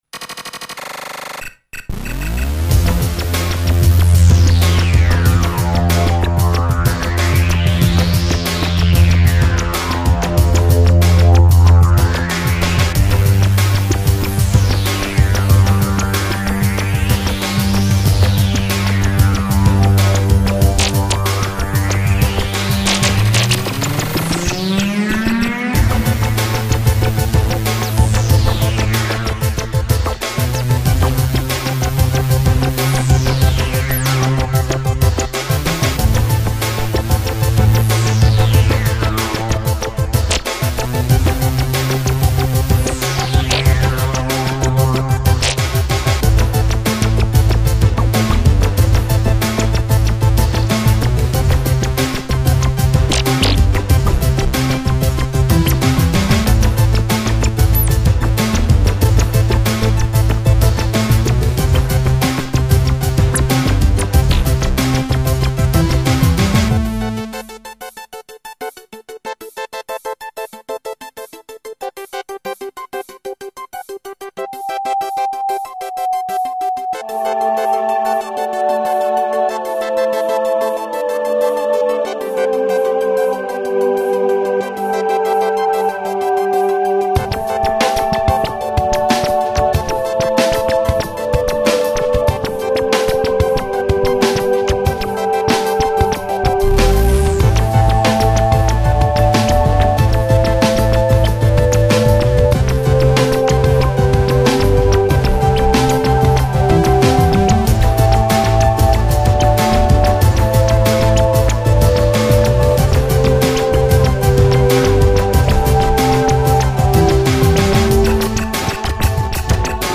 digi-percussion